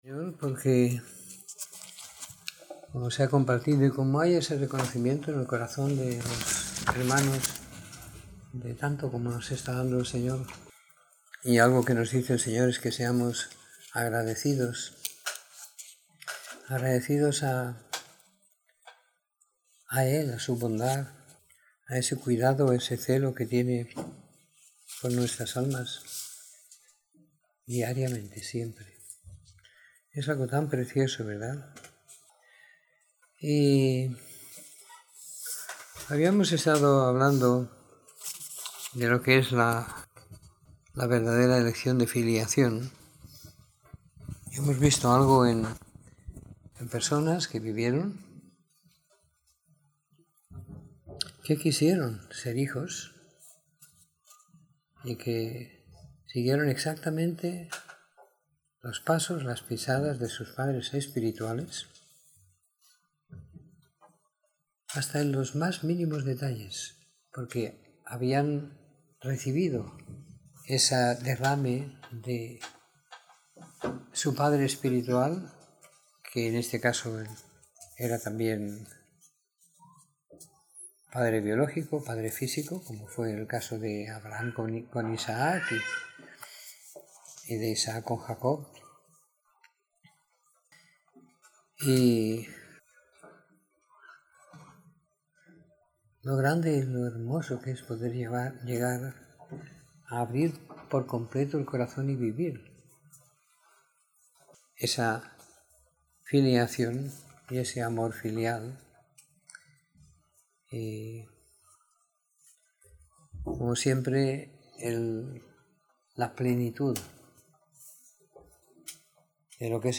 Domingo por la Tarde . 25 de Octubre de 2015